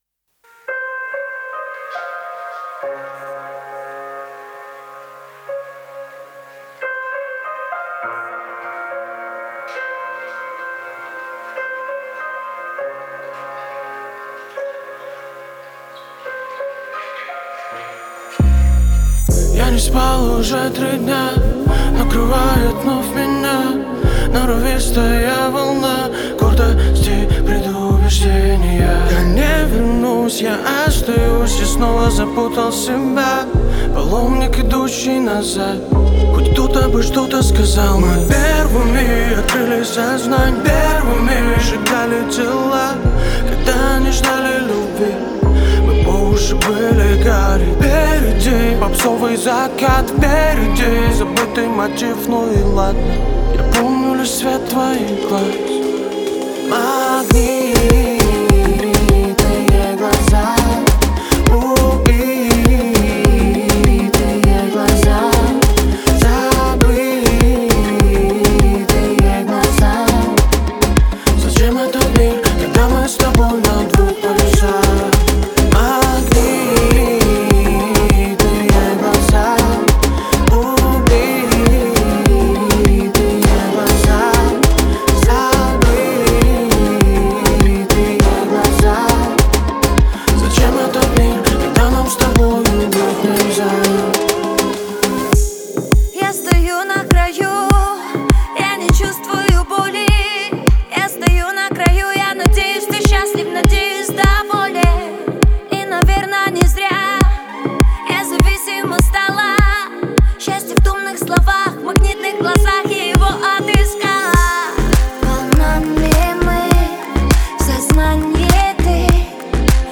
• Жанр: New Rus / Русские песни